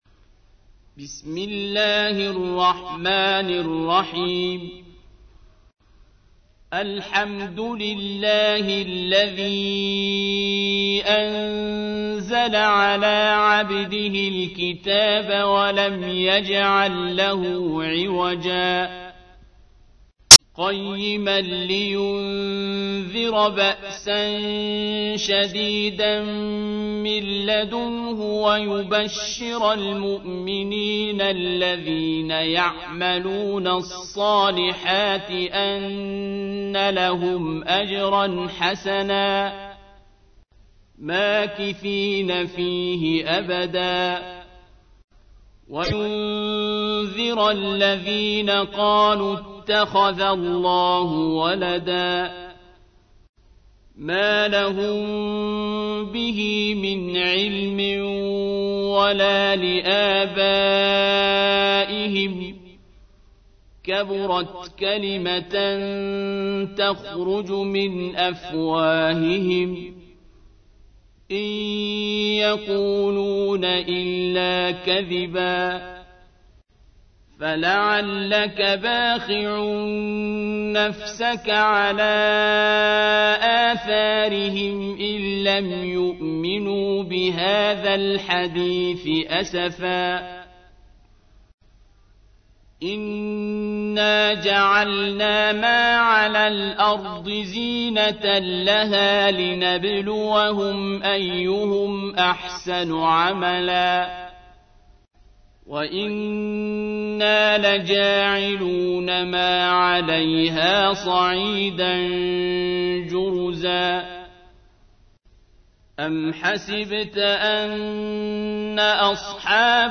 تحميل : 18. سورة الكهف / القارئ عبد الباسط عبد الصمد / القرآن الكريم / موقع يا حسين